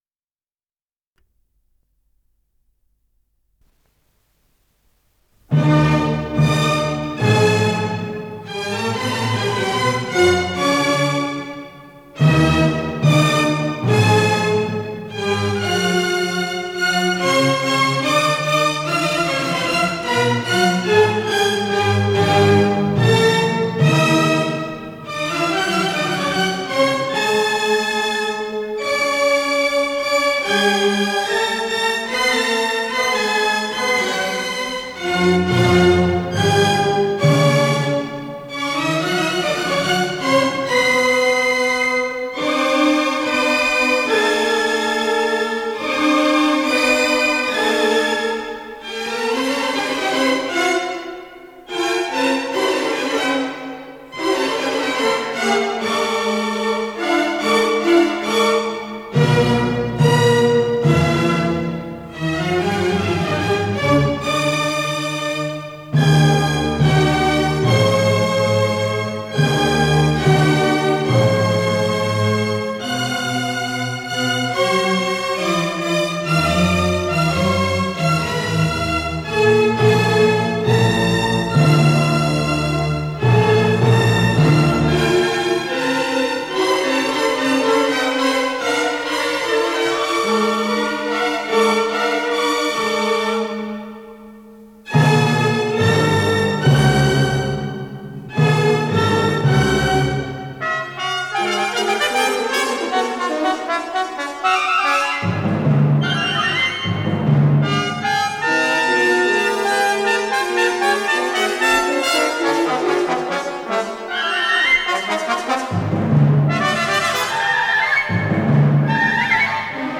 Исполнитель: Симфонический оркестр Всесоюзного радио и Центрального телевидения